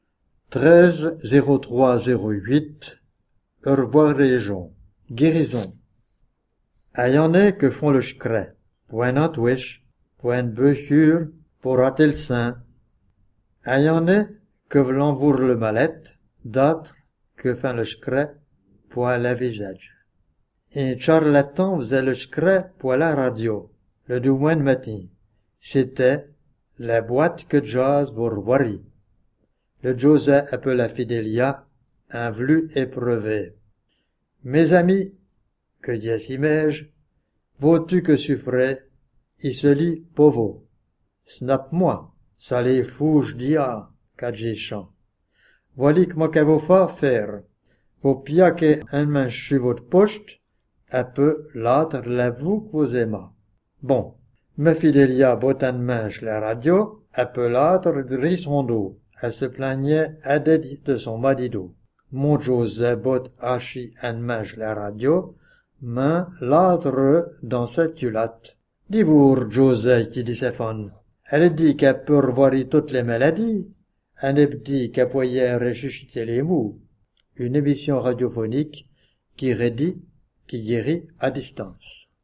Patois Jurassien
Ecouter la chronique lue par